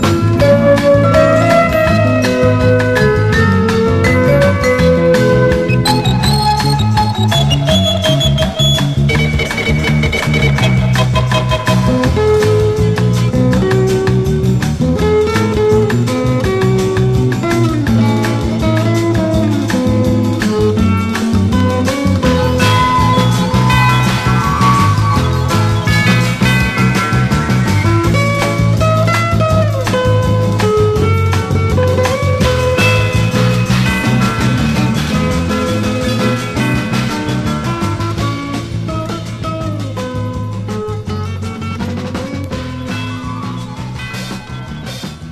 EASY LISTENING / EASY LISTENING / SOFT ROCK / CHORUS
立体的構築の音響。美しい世界観でいて狂気的な展開。